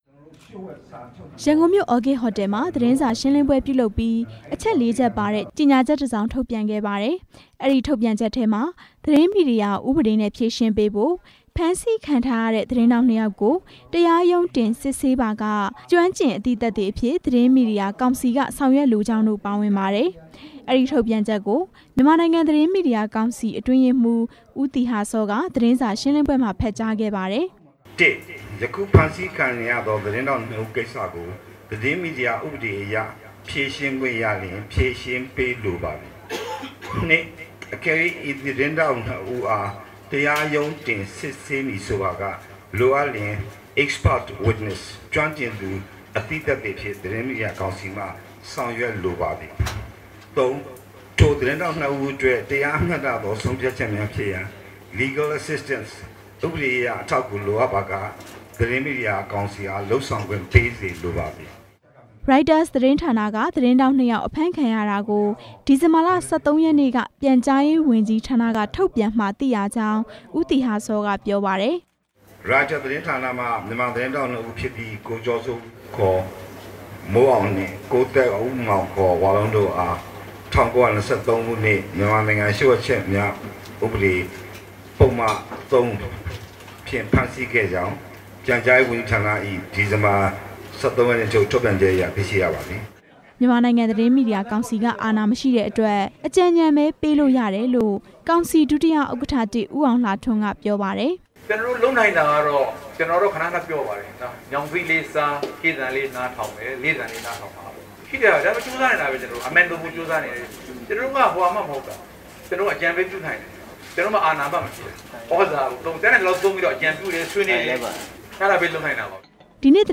ရိုက်တာသတင်းဌာနက သတင်းထောက် ၂ ဦး ဖမ်းဆီးခံထားရတာနဲ့ ပတ်သက်ပြီး မြန်မာနိုင်ငံ သတင်းမီဒီယာကောင်စီရဲ့ ရပ်တည်ချက်ကို ဒီနေ့ သတင်းစာရှင်းလင်းပွဲ ပြုလုပ်ပြီး ကောင်စီ တာဝန်ရှိသူတွေက ပြောကြားခဲ့ပါတယ်။